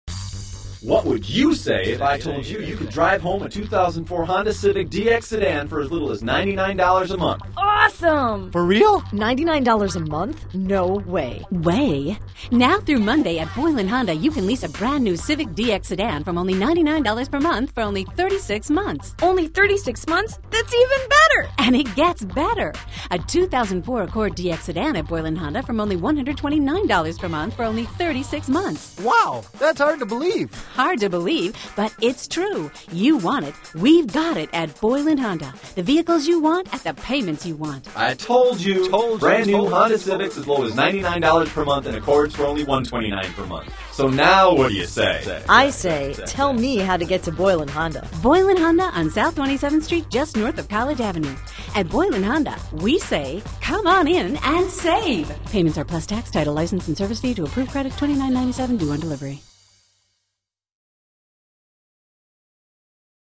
Boyland Honda Radio Commercial